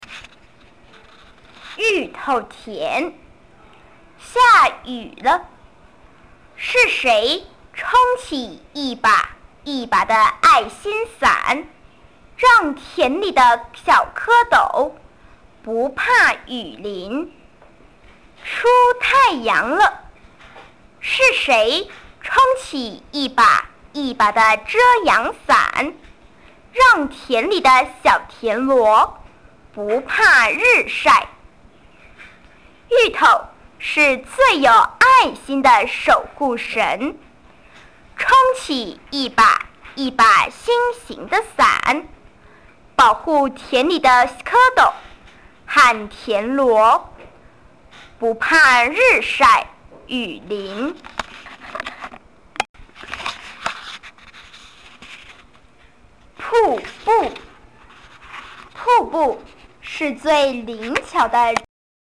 110學年校內語文競賽 一年級國語童詩朗讀稿及聲音檔 歡迎下載運用